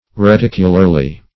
reticularly - definition of reticularly - synonyms, pronunciation, spelling from Free Dictionary Search Result for " reticularly" : The Collaborative International Dictionary of English v.0.48: Reticularly \Re*tic"u*lar*ly\, adv.
reticularly.mp3